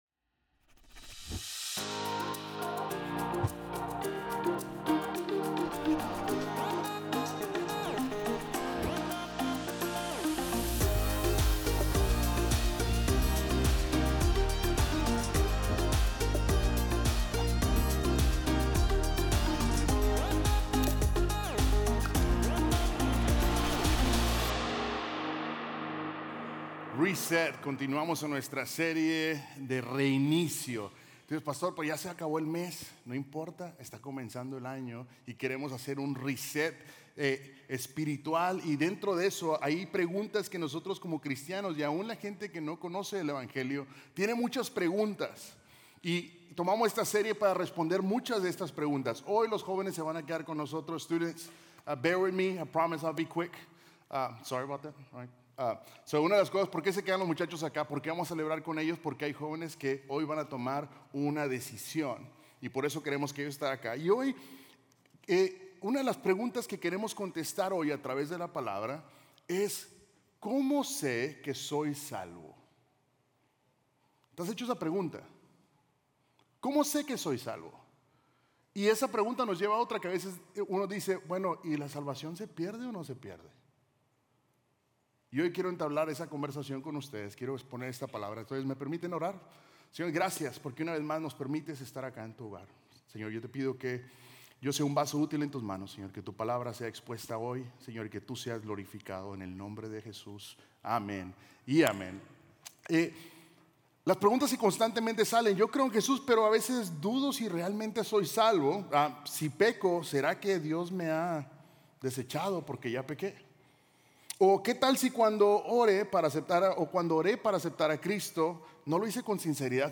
Un mensaje de la serie "Reset - NK."